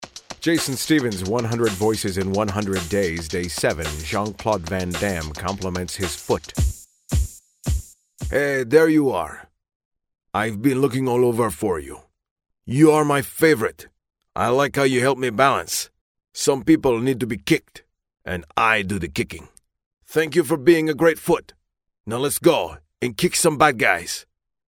For day seven, I went with my Jean-Claude Van Damme impression.
Tags: Jean-Claude Van Damme impression